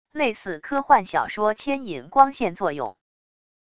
类似科幻小说牵引光线作用wav音频